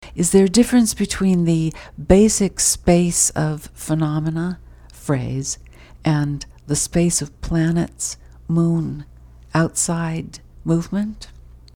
In the first 1.75 seconds of the clip, the poet races through eight syllables, virtually deleting two unstressed vowels and hitting a pitch peak of 248Hz at the transition into the second syllable of "difference" (here pronounced difrints). The next eight-syllable segment, by contrast, takes more than 3 seconds to voice and contains three stressed sylllables (written in all caps here): "BASic SPACE of pheNOMena."
On each side of the word "phrase" the poet leaves a pause of about half a second, and, while keeping her volume levels consistent (peaking in the 75 to 85 dB range), she sharply decreases her tone (creating that "U" shape in the pitch transcript, see below).
By contrast, when she voices the very similar vowel in the first syllable of "movement" less than two seconds later, she holds her pitch in the 160-170 level (her norm), rising toward 220Hz on the unstressed final syllable to realize the interrogative pitch pattern operative in English.